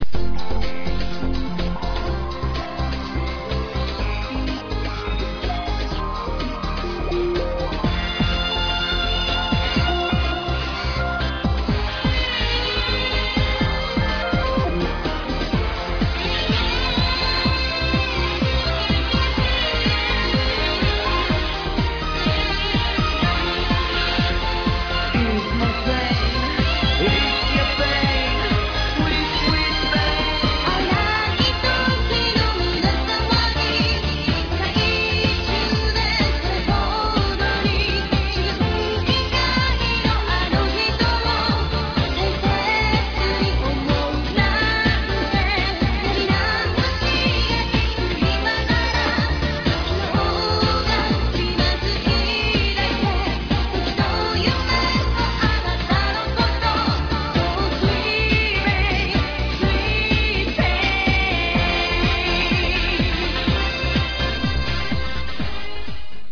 ( 主唱兼鍵盤 )
( 女主唱 )
( RAPPER )